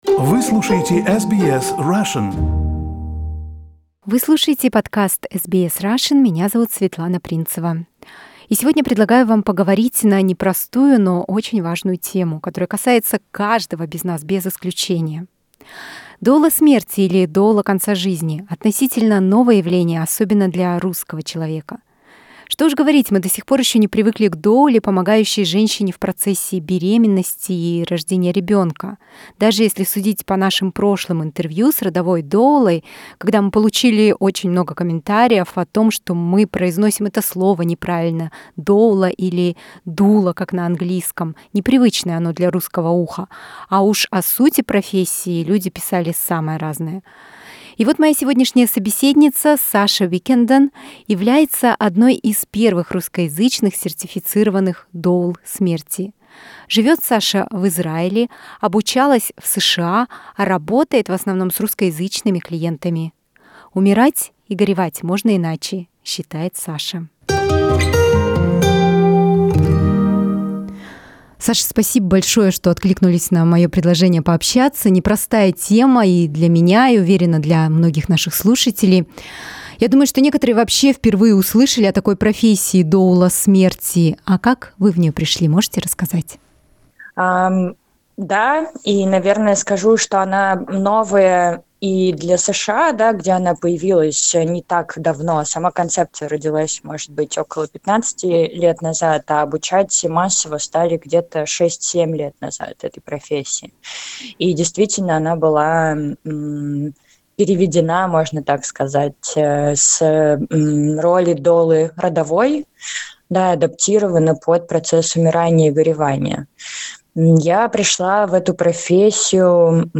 In interview